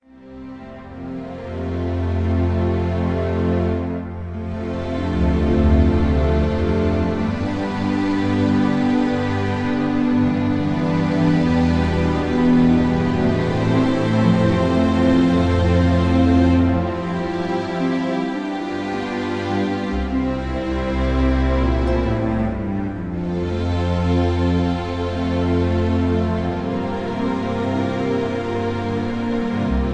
(Key-F, Tono de F) Karaoke MP3 Backing Tracks